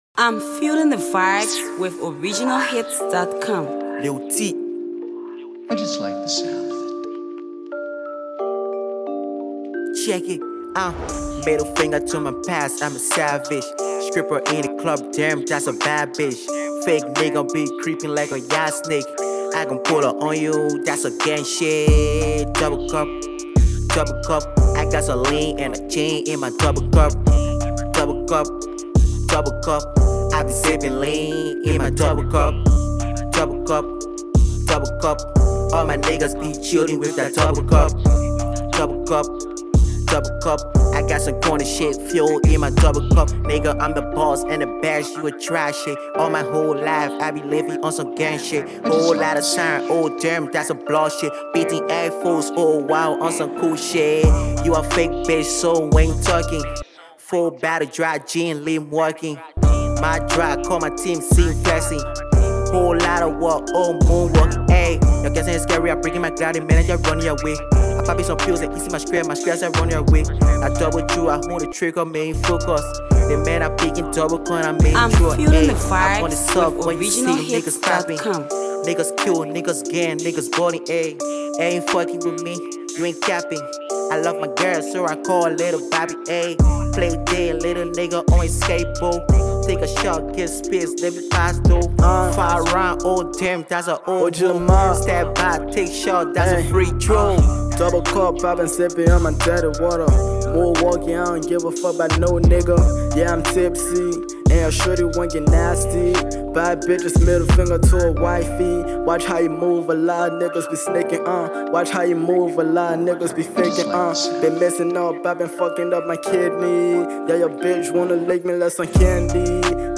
trap song